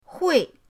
hui4.mp3